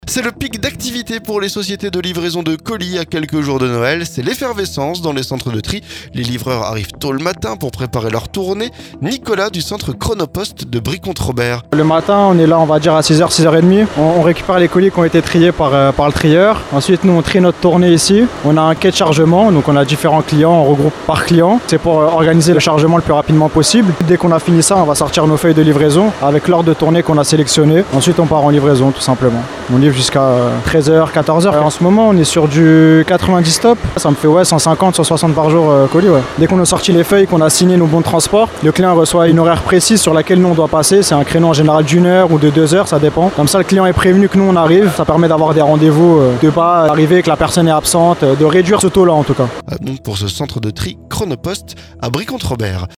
NOËL - Reportage dans le centre de tri Chronopost de Brie-Comte-Robert